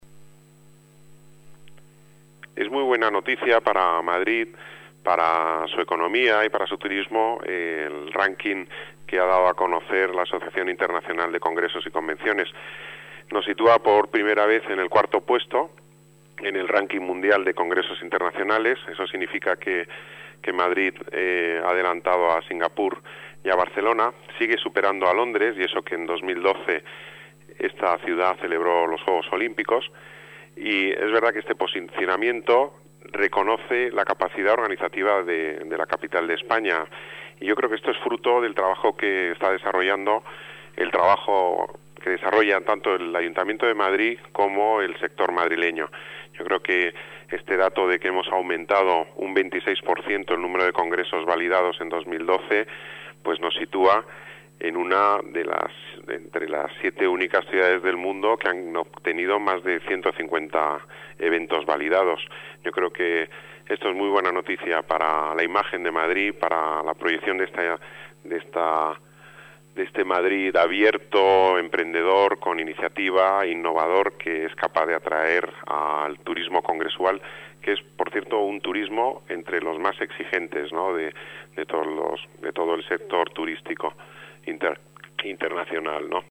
Nueva ventana:Declaraciones de Pedro Corral, delegado de Las Artes, Deportes y Turismo